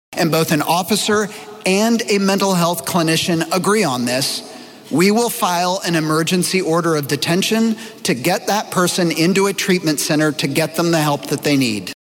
CLICK HERE to listen to commentary from Tulsa Mayor G.T. Bynum